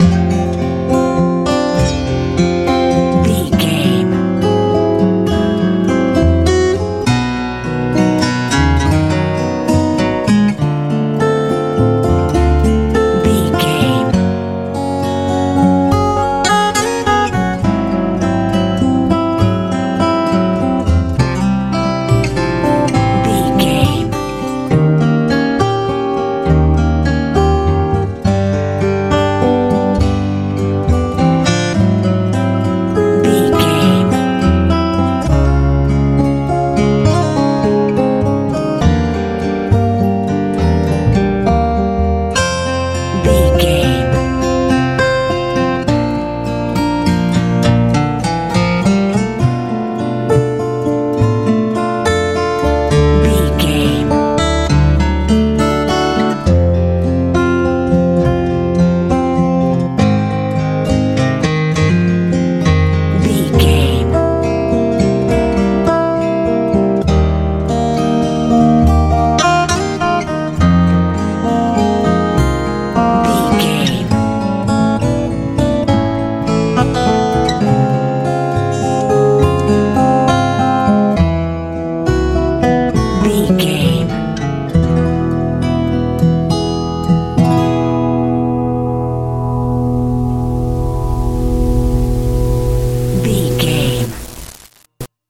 acoustic guitar ballad feel
Ionian/Major
light
acoustic guitar
soothing
relaxed